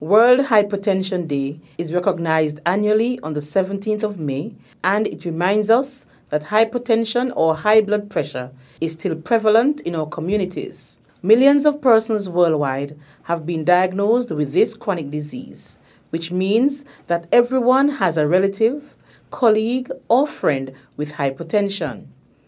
That was Junior Minister of Health on Nevis, the Hon. Hazel Brandy-Williams.
Her remarks were made in an Address to mark the observance of World Hypertension Day 2021.